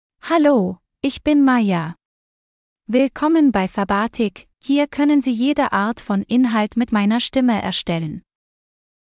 Maja — Female German (Germany) AI Voice | TTS, Voice Cloning & Video | Verbatik AI
FemaleGerman (Germany)
Maja is a female AI voice for German (Germany).
Voice sample
Listen to Maja's female German voice.
Maja delivers clear pronunciation with authentic Germany German intonation, making your content sound professionally produced.